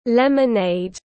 Nước chanh tiếng anh gọi là lemonade, phiên âm tiếng anh đọc là /ˌlem.əˈneɪd/
Lemonade /ˌlem.əˈneɪd/